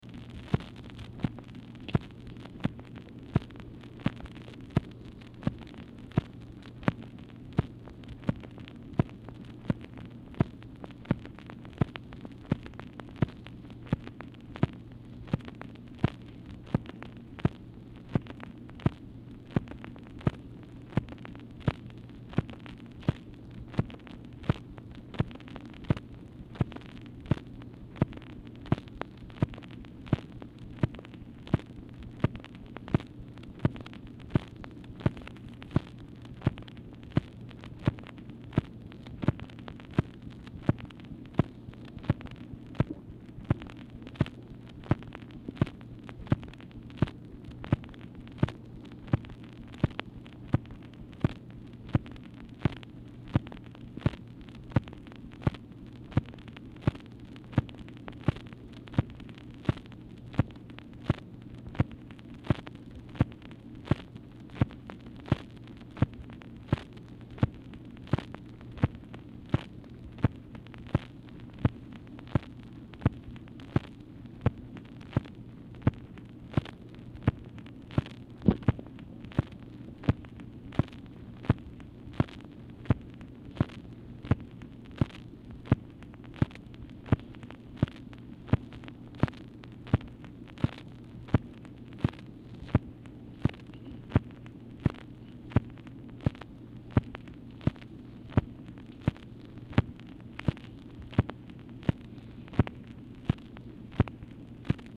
Telephone conversation # 11640, sound recording, MACHINE NOISE, 3/14/1967, time unknown | Discover LBJ
Format Dictation belt
Oval Office or unknown location